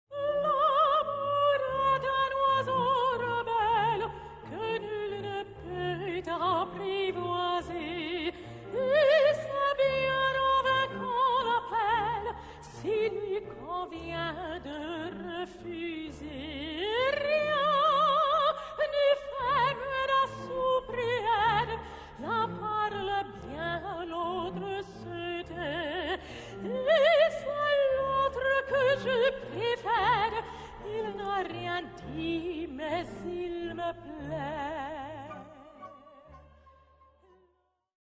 • a quale registro vocale appartiene la voce della cantante?